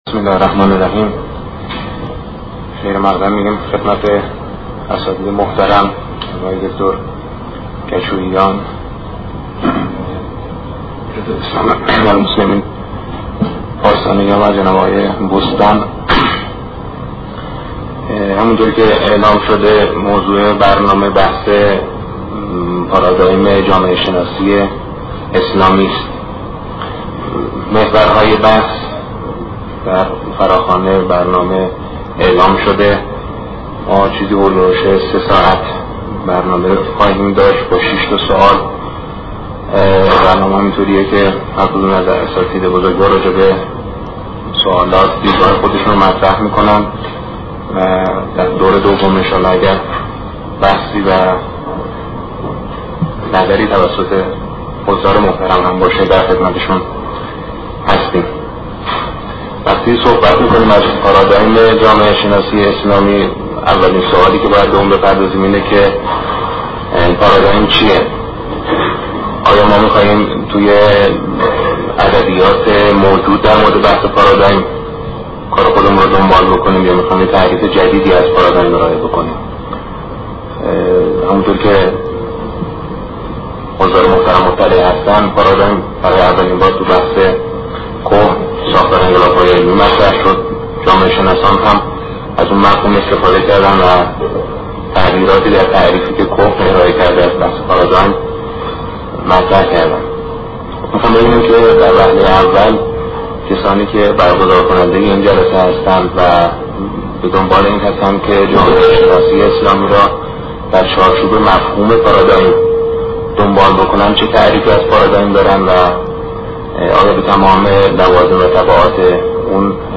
فایل حاضر سخنرانی